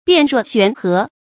辨若懸河 注音： ㄅㄧㄢˋ ㄖㄨㄛˋ ㄒㄨㄢˊ ㄏㄜˊ 讀音讀法： 意思解釋： 辨，通「辯」。